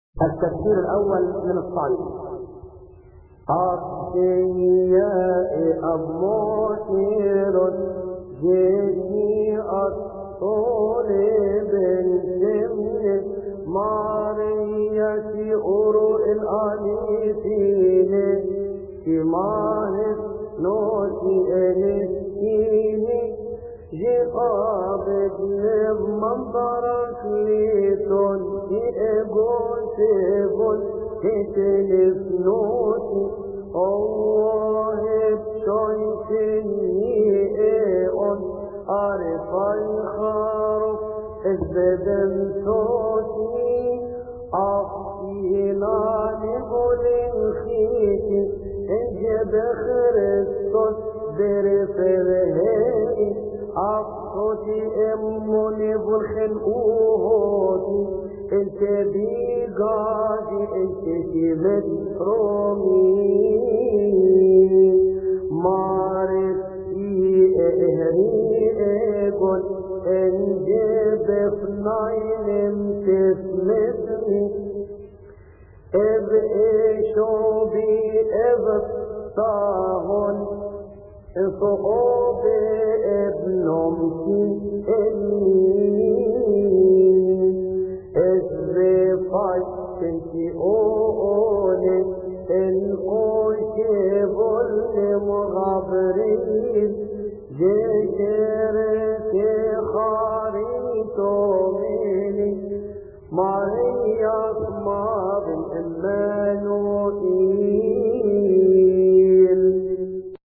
يصلي في تسبحة عشية أحاد شهر كيهك